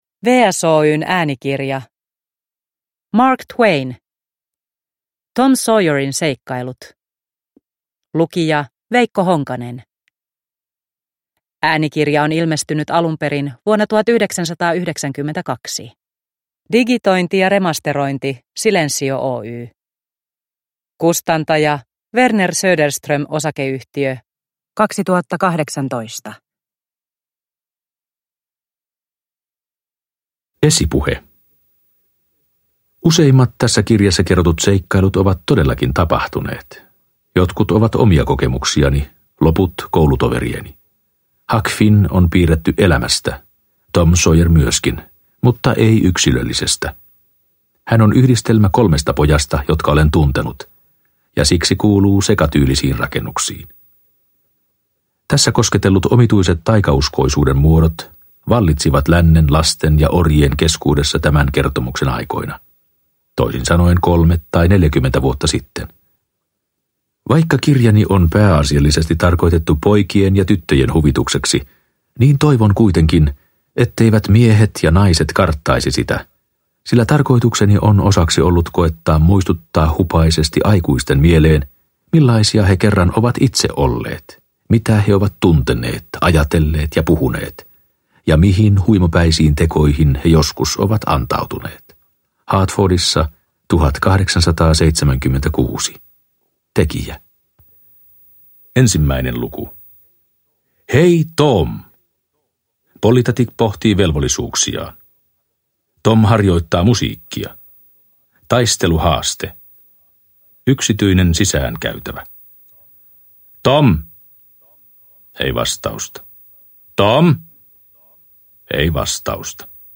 Tom Sawyerin seikkailut – Ljudbok – Laddas ner